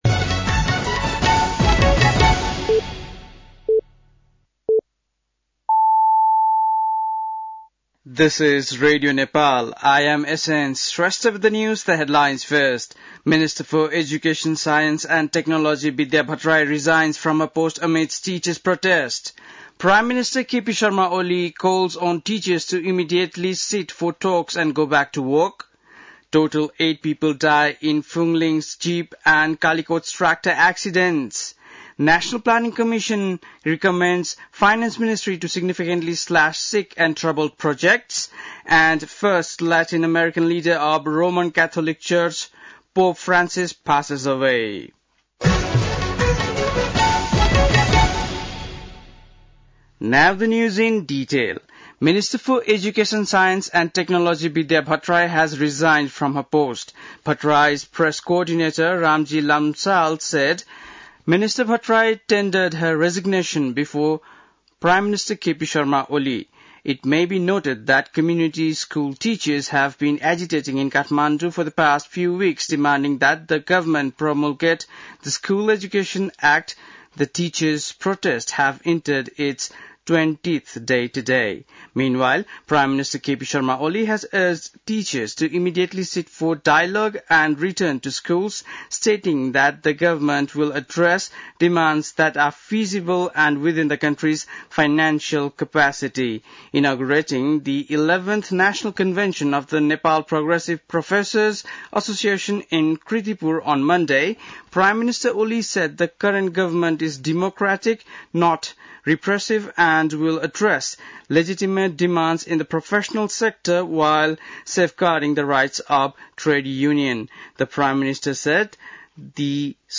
बेलुकी ८ बजेको अङ्ग्रेजी समाचार : ८ वैशाख , २०८२
8.-pm-english-news.mp3